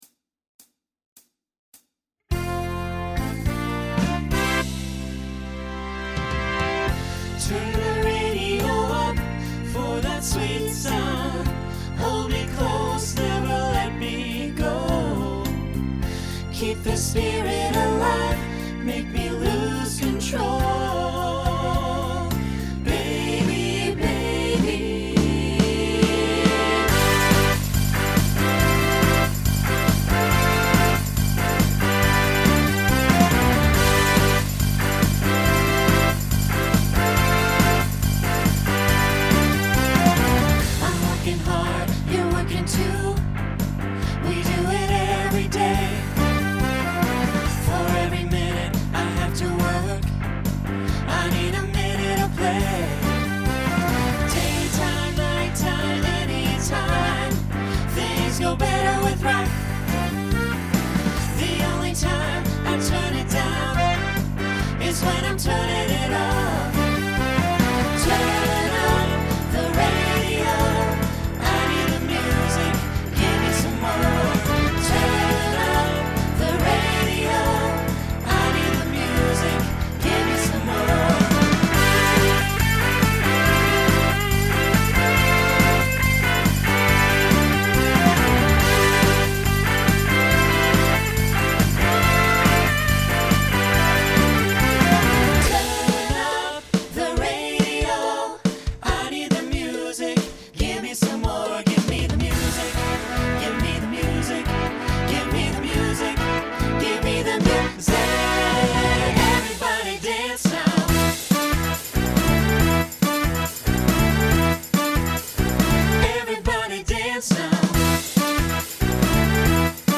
Genre Pop/Dance , Rock
Voicing SAB